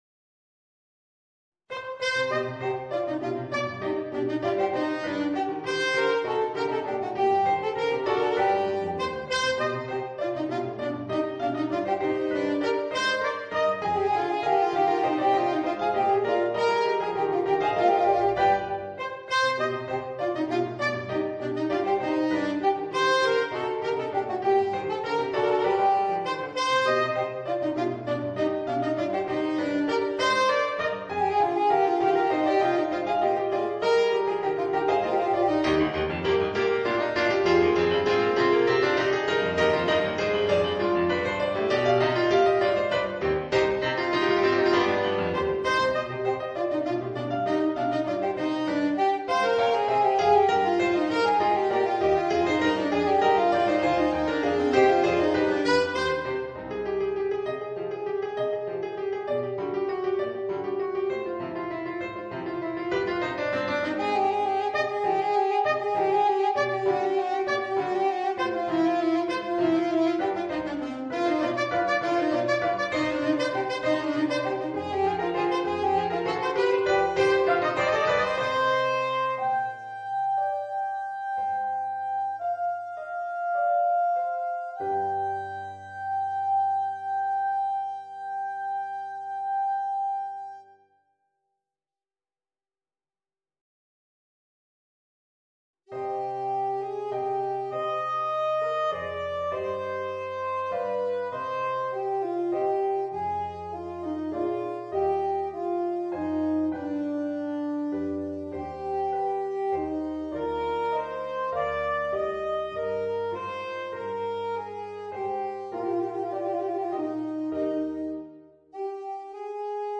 Voicing: Alto Saxophone and Organ